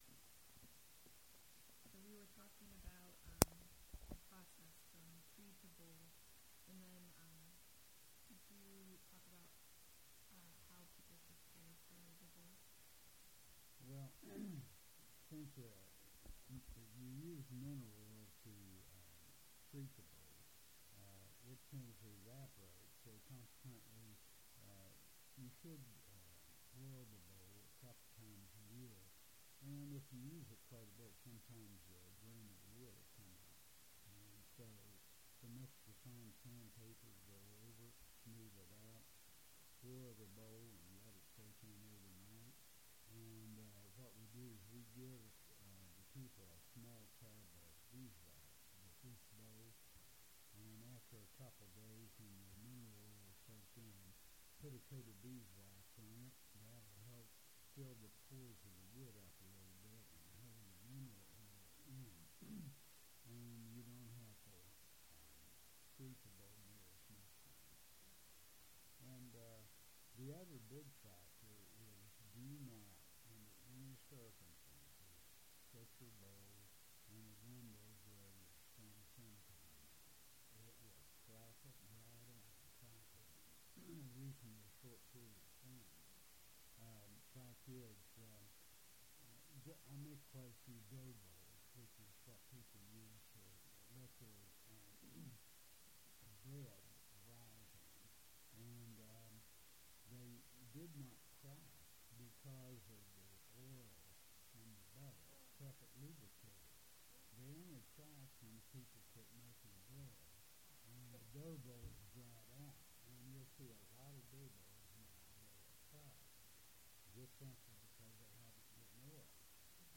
This is part 4 of 5 files constituting the full interview. Subject: Woodworking tools, woodworking, Furniture making Rights: In Copyright - Educational Use Permitted Location: Berkeley County (W. Va.), Martinsburg (W. Va.)